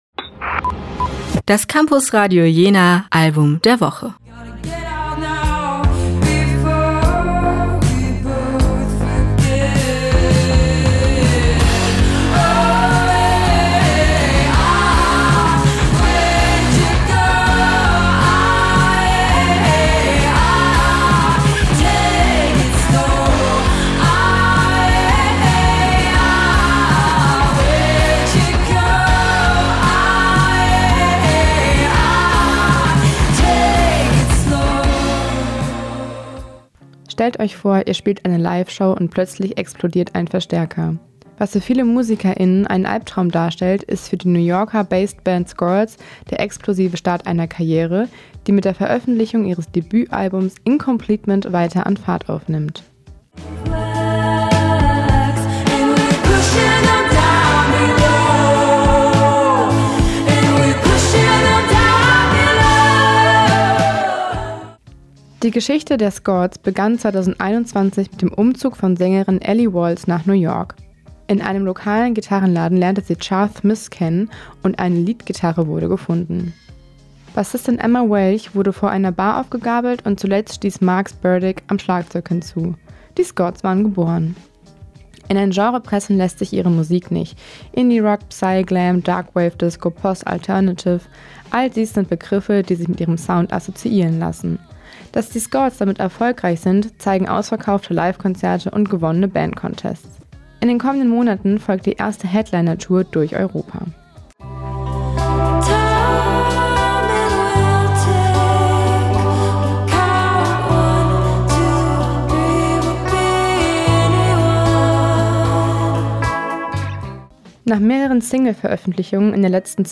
Wir finden, diese Mischung macht’s. Die SKORTS mit “Incompletement” sind daher ganz klar unser Campusradio Jena Album der Woche.